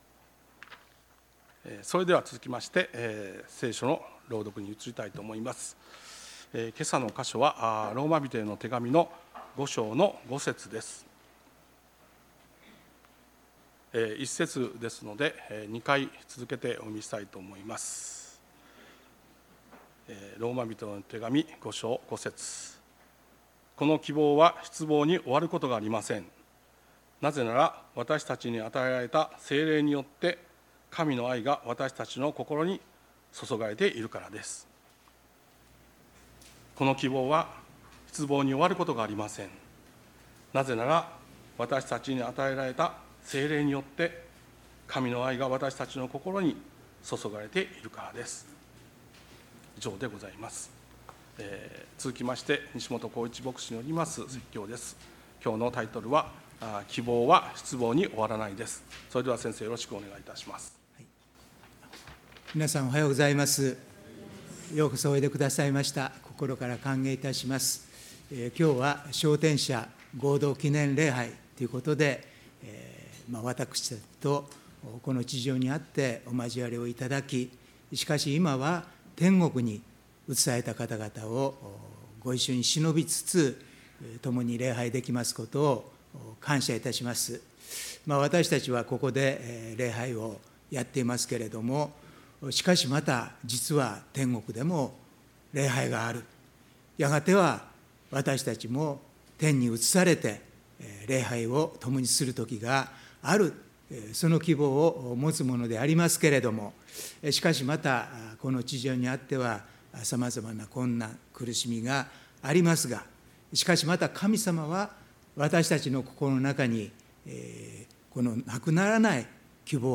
礼拝メッセージ「希望は失望に終わらない」│日本イエス・キリスト教団 柏 原 教 会